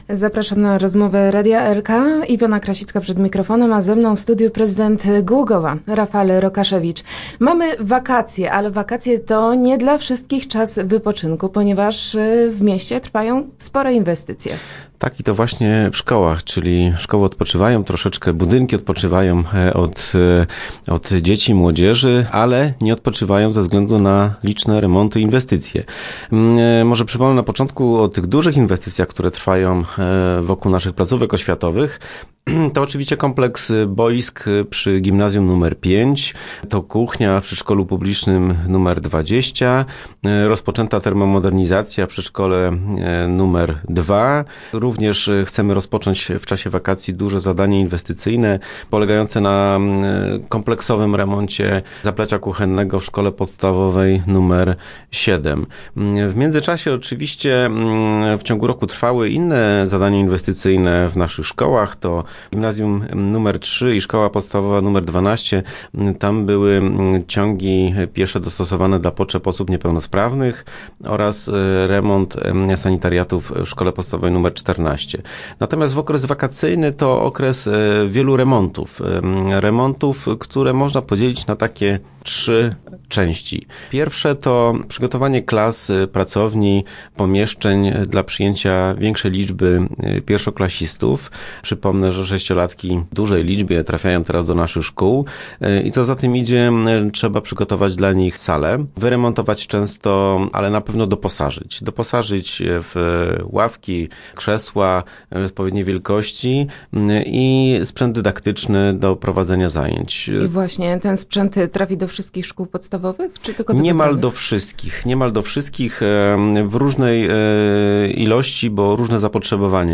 Gdzie i jak przebiegają prace? O tym rozmawialiśmy z prezydentem Głogowa, Rafaelem Rokaszewiczem.